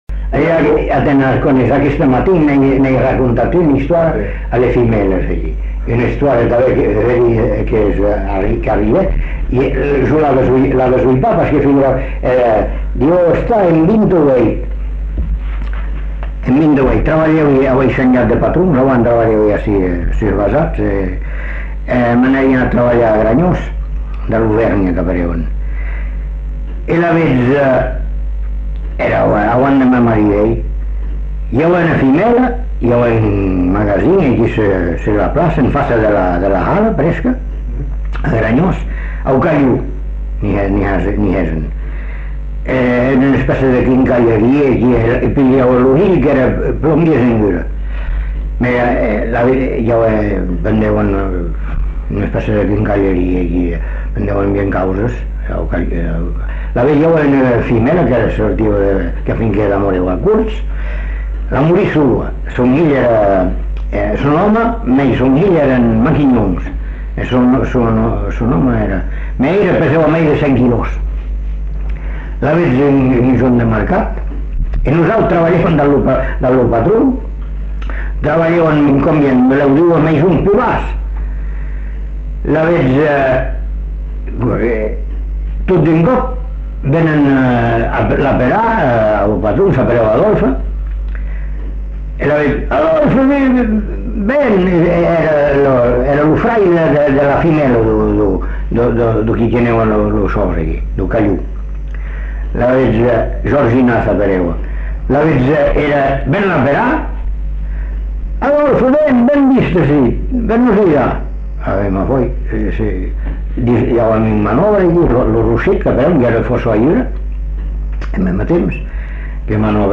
Aire culturelle : Bazadais
Lieu : Bazas
Genre : récit de vie